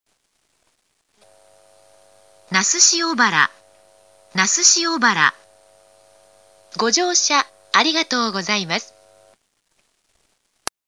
スピーカーの配置が特徴的で、駅員用放送と自動放送用が分離しているのですが、ほぼ必ず併設されています。